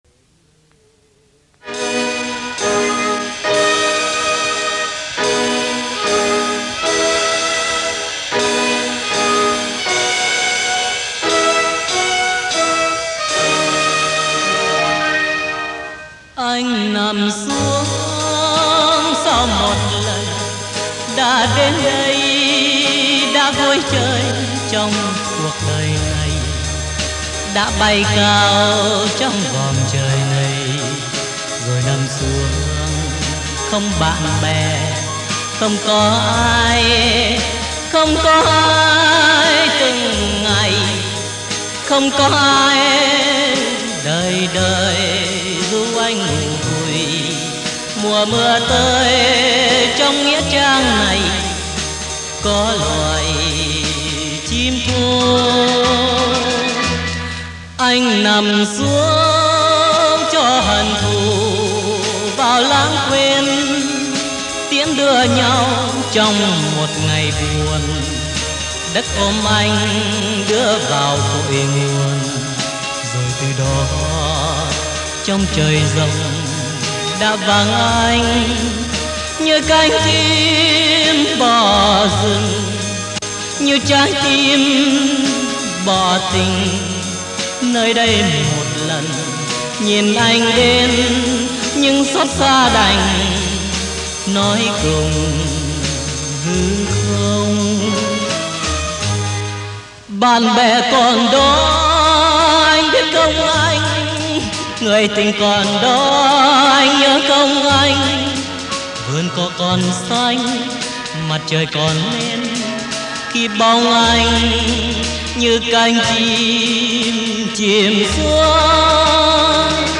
Trình Bày: Miên Đức Thắng (Pre 75)
Chúng tôi sẽ cố sưu tập bản nhạc được hát bởi một ca sĩ miền Nam trước 1975 để ý nghĩa bảo tồn được trọn vẹn, dù rằng cũng bản nhạc đó, với phần kỹ thuật, phối âm , phối khí và ca sĩ trẻ hơn thực hiện tại hải ngọai sau này có hay hơn nhiều.